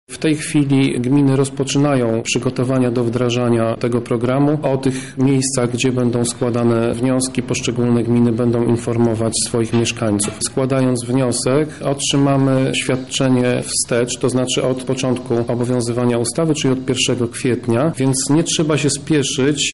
– mówi Tomasz Pitucha, pełnomocnik wojewody do spraw rodziny